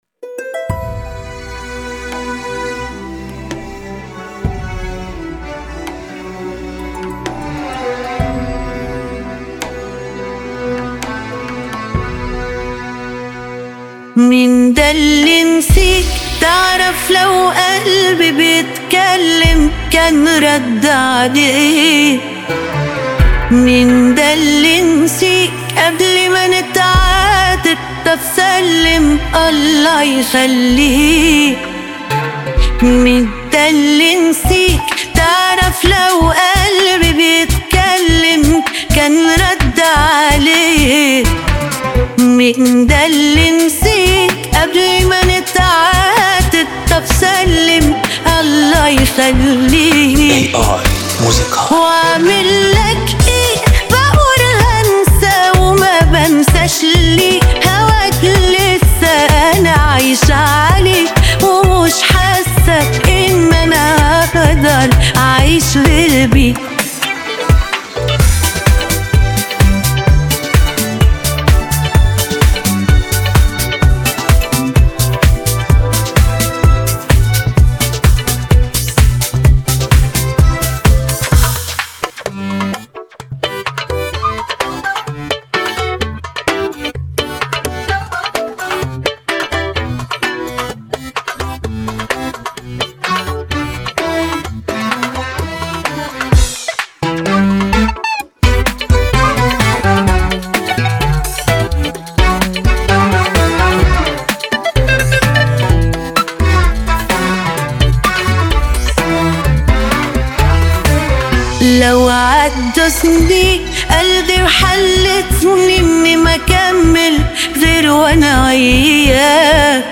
بهترین آهنگ های ساخته شده توسط هوش مصنوعی